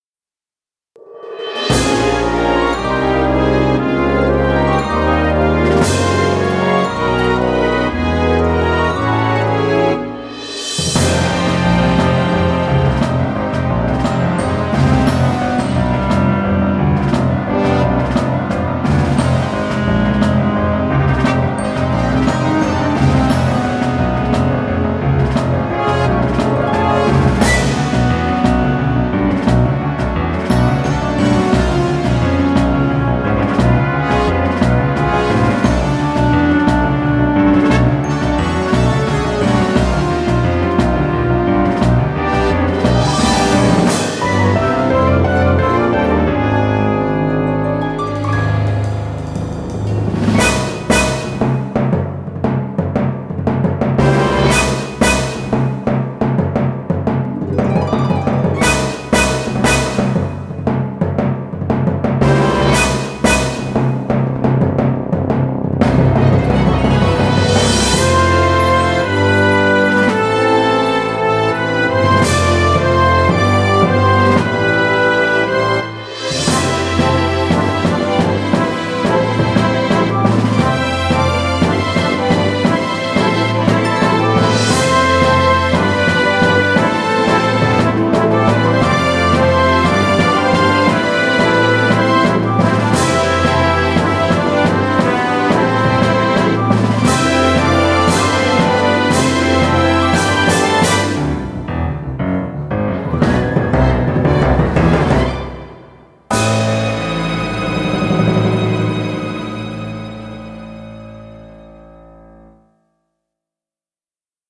Genre: Game.